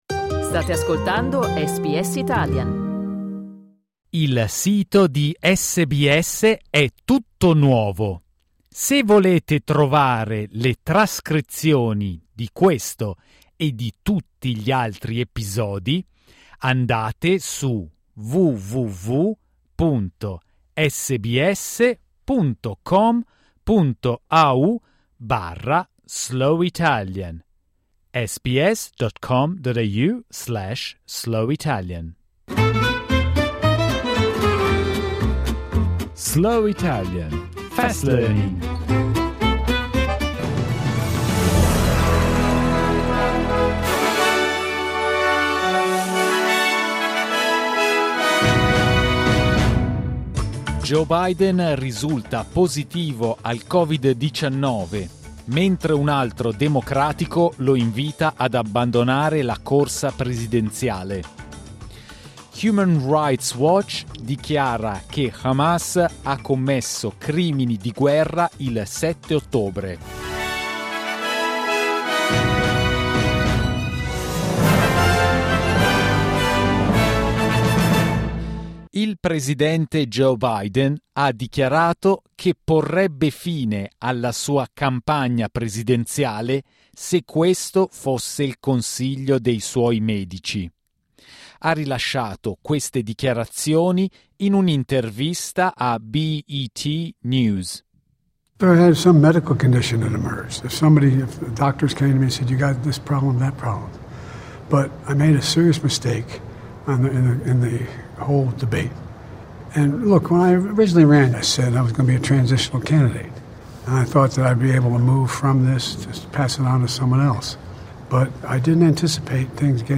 SBS Italian's News bulletin, read slowly.